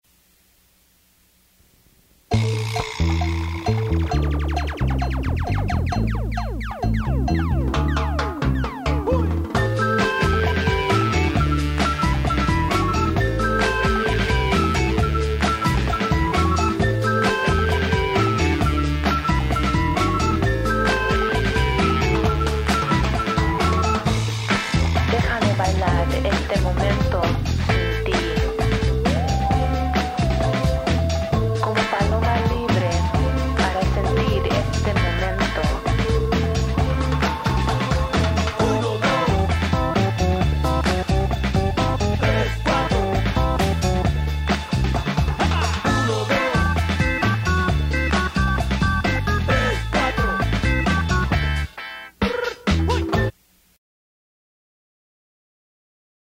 Guest Vocals & spoken word lyrics